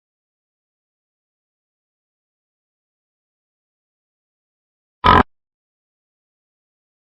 Cat Purring
Cat Purring is a free animals sound effect available for download in MP3 format.
484_cat_purring.mp3